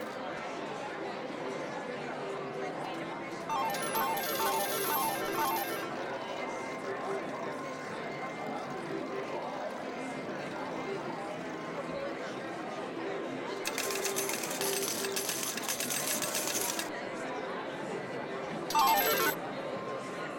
Ambiance Casino de Las Vegas (Broadcast) – Le Studio JeeeP Prod
Bruits d’ambiance dans une salle de casino.